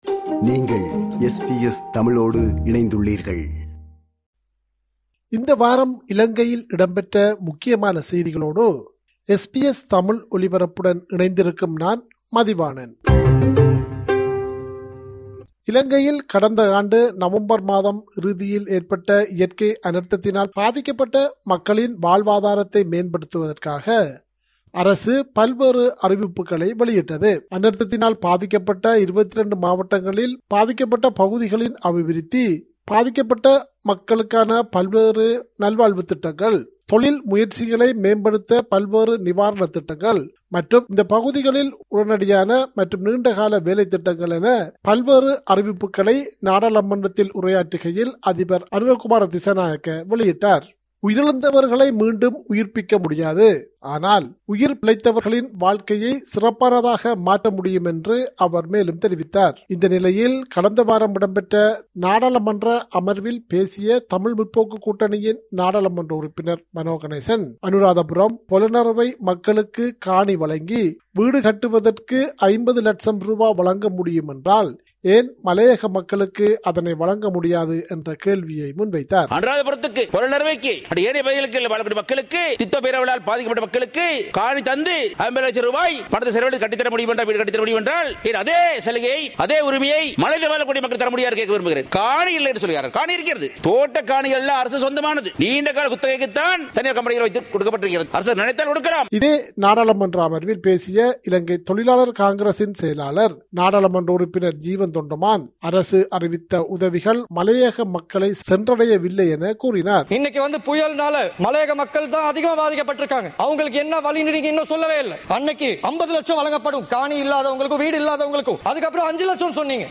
இலங்கை: இந்த வார முக்கிய செய்திகள்